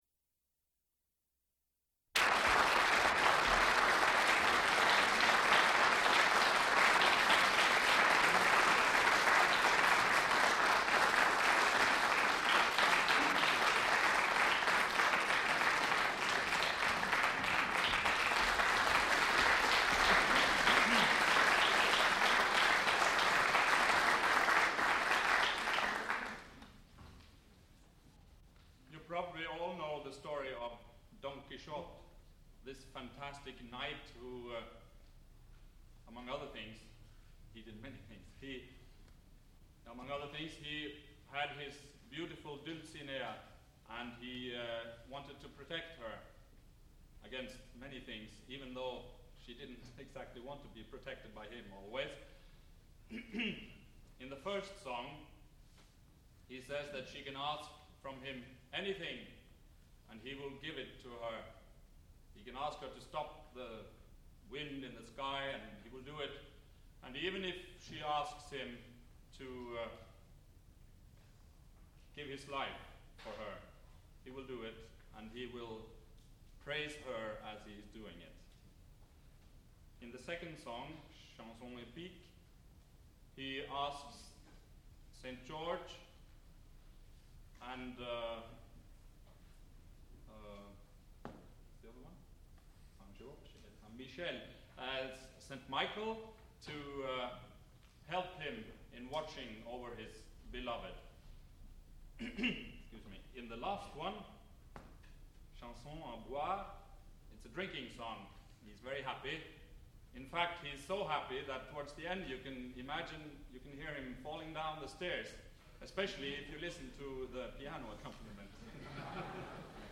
sound recording-musical
classical music
baritone
piano